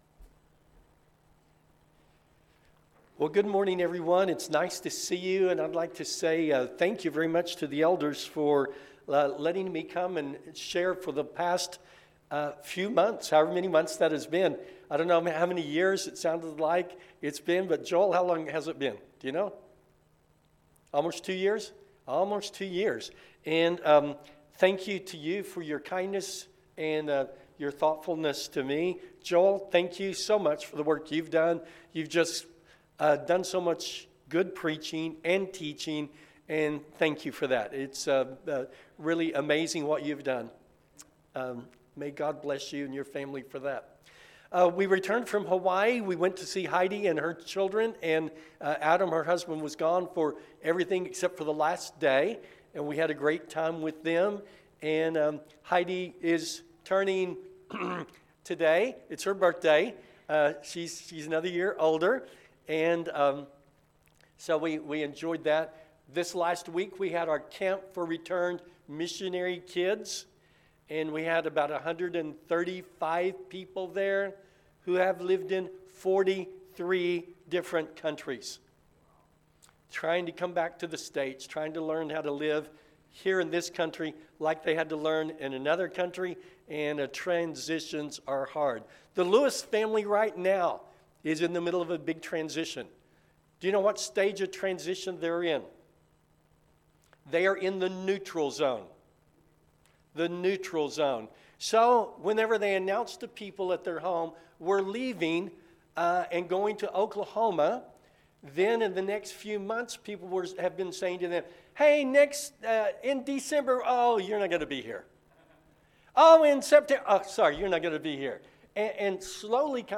Let’s Move Forward – Sermon